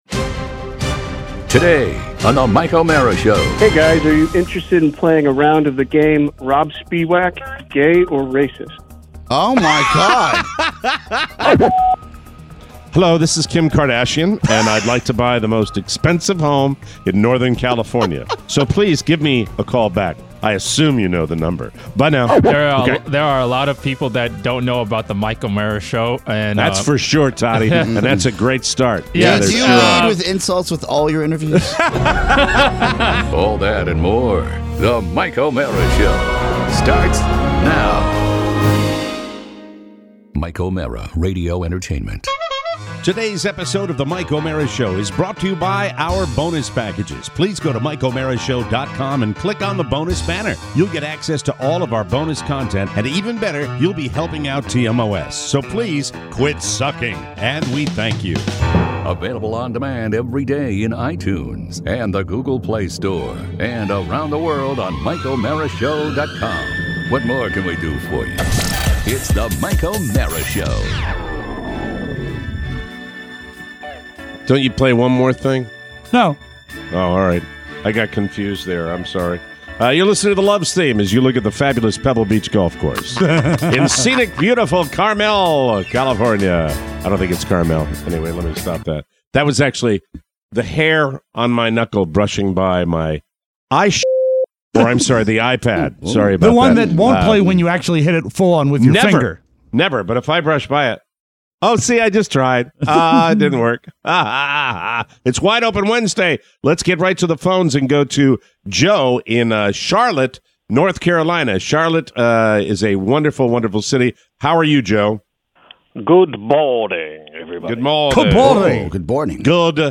“WOW” means Wide Open Wednesday… and your calls. Plus, a live on-air interview with an old friend.